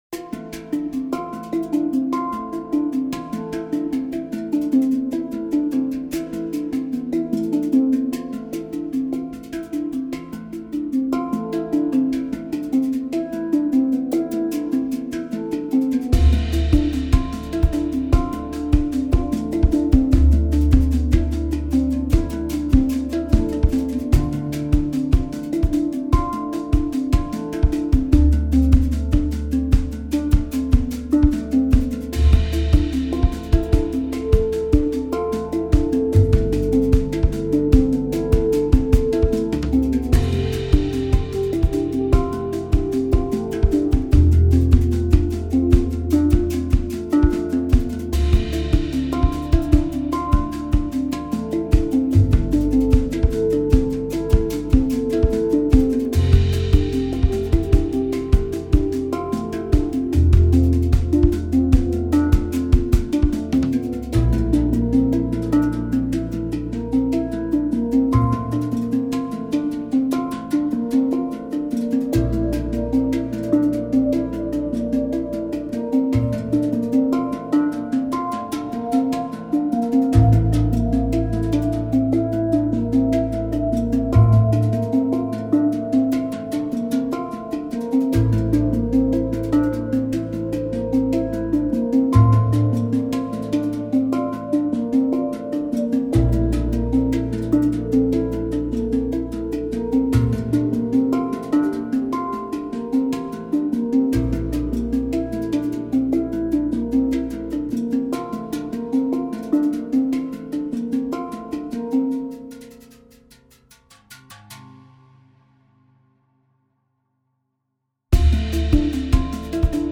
percussion and hangdrums